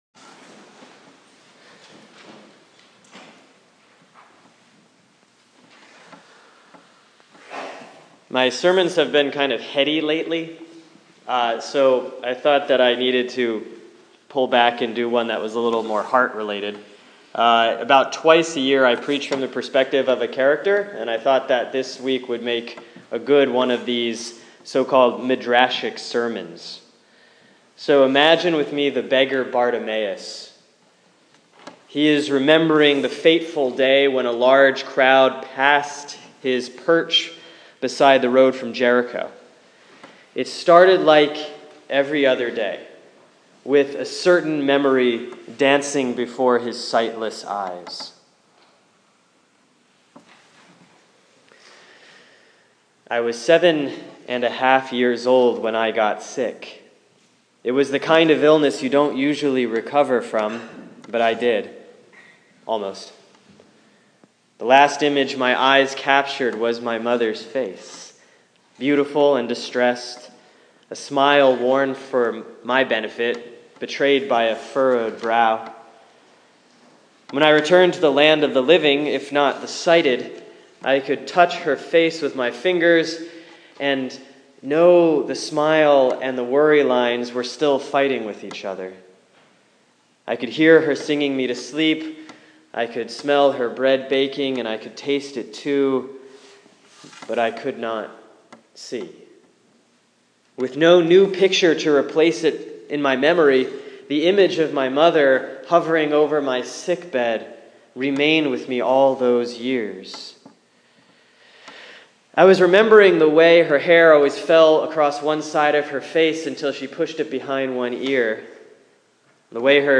Sermon for Sunday, October 25, 2015 || Proper 25B || Mark 10:46-52